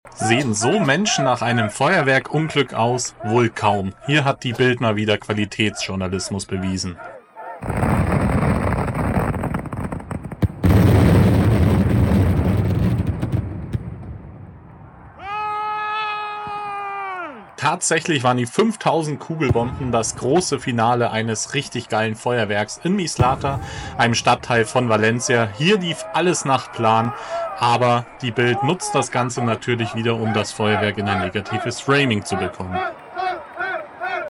XXL Feuerwerk in Mislata Valencia